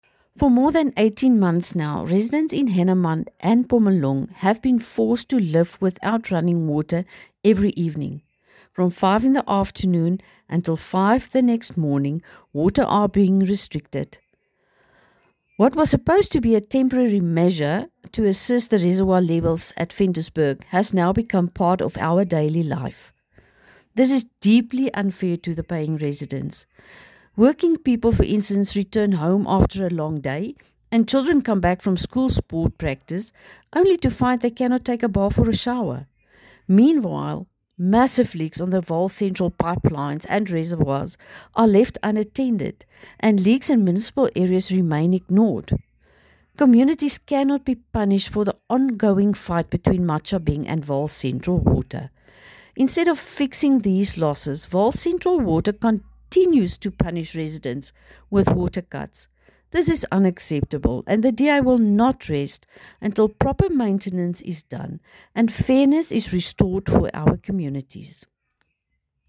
Afrikaans soundbites by Cllr Maxie Badenhorst and